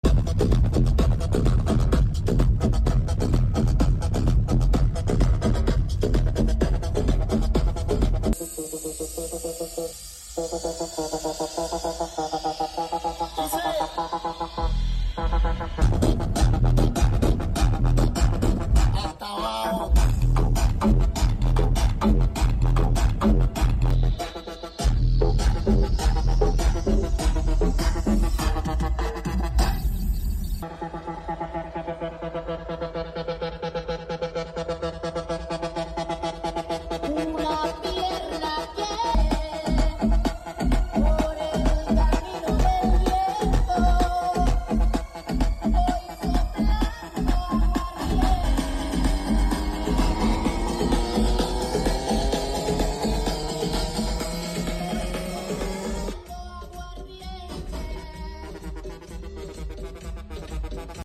VW Jetta Variant con subwoofers sound effects free download
VW Jetta Variant con subwoofers T-Rex de Rock Series en el aniversario del Corsa Club León.